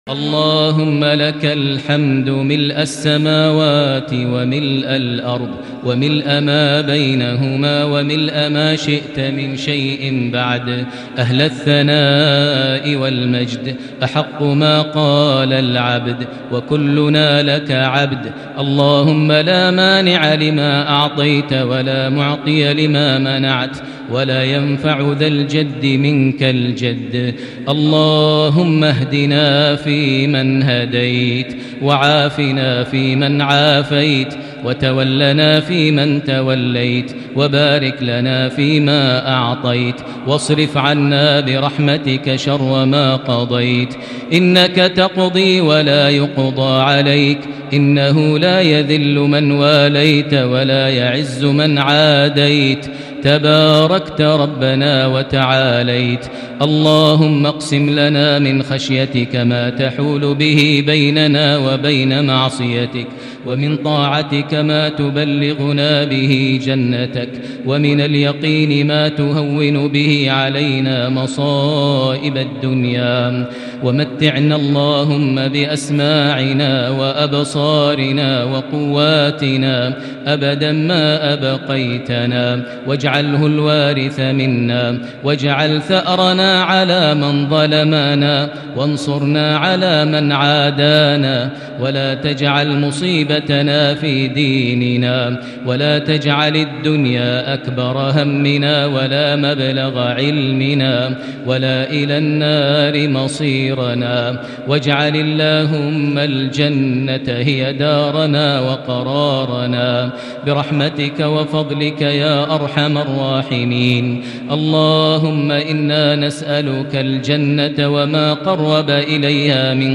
دعاء ليلة 17 رمضان 1441هـ > تراويح الحرم المكي عام 1441 🕋 > التراويح - تلاوات الحرمين